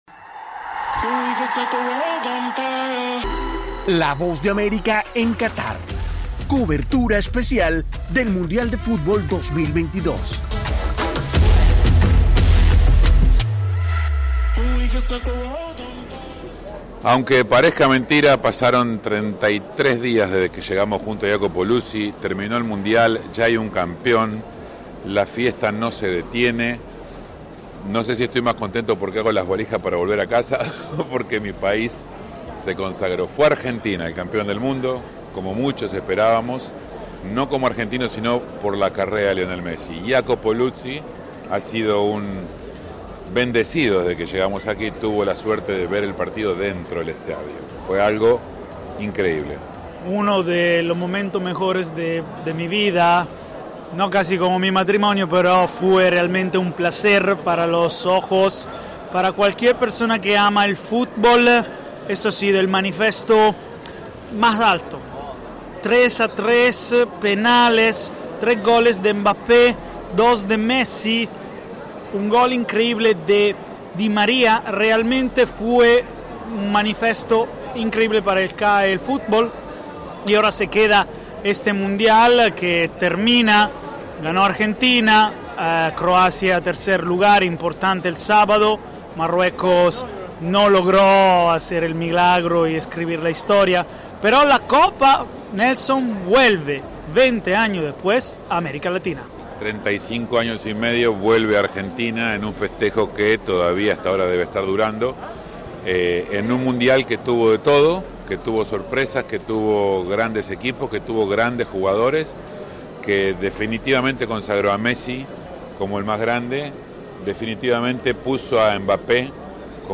Argentina logró el máximo título del Futbol Mundial y la Copa Jules Rimet vuelve a esa nación sudamericana en medio de una gran algarabía. Nuestros enviados especiales a Doha